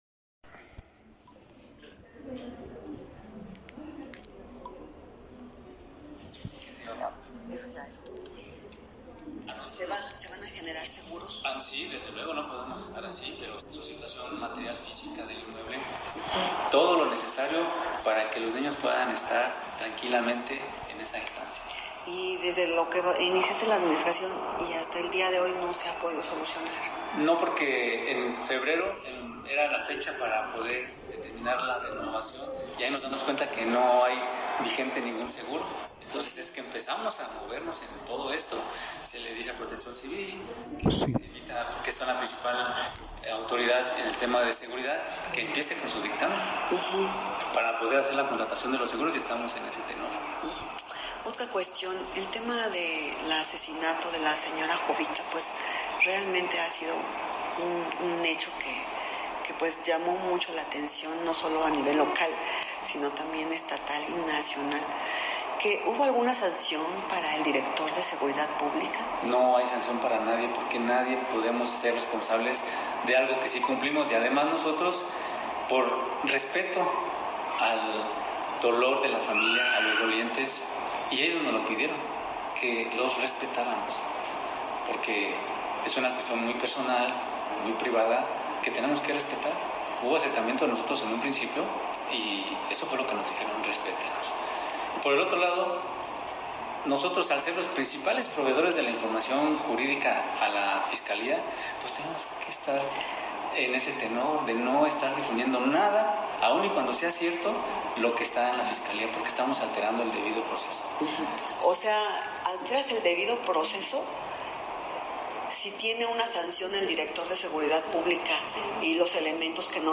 Enojado el alcalde, Zarate Nieves explicó que una sanción administrativa al director de policía no le correspondía porque hay un consejo de honor y justicia, además de no haber causa alguna.
Entrevista-alcalde-Comonfort-3.mp3